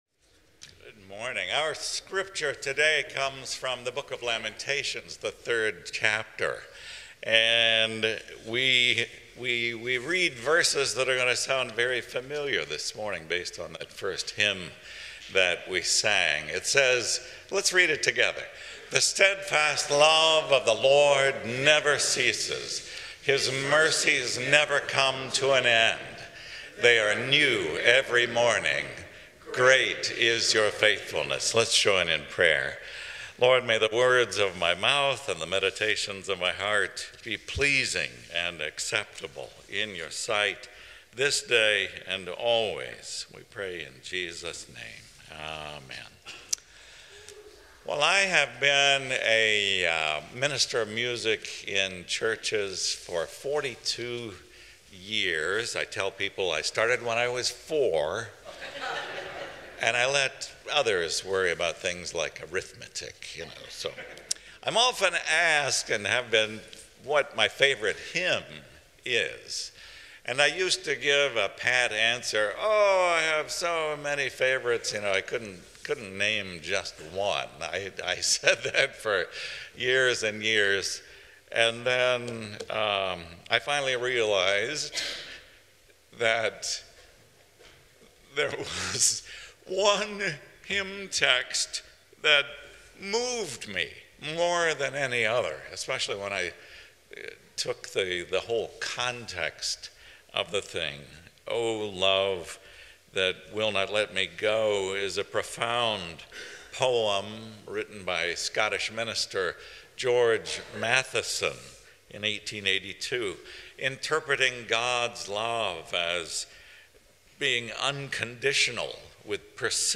Sermon Reflections: